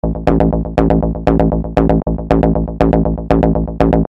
Techno Bass